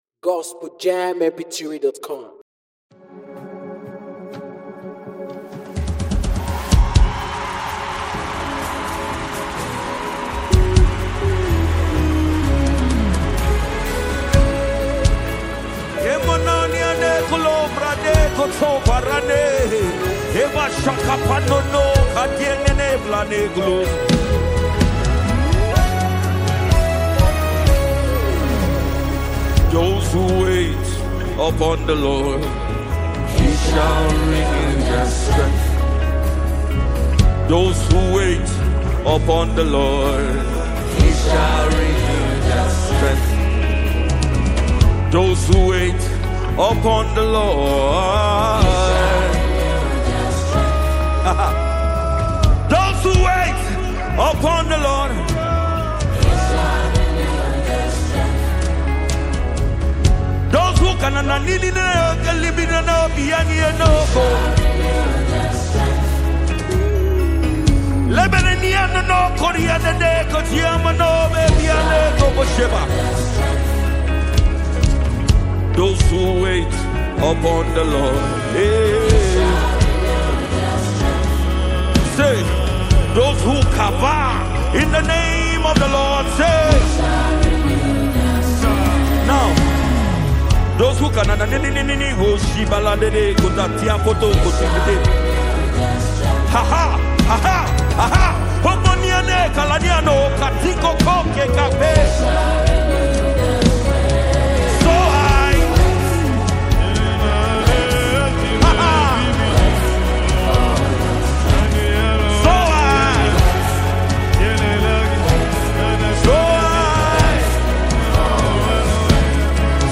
a powerful worship sound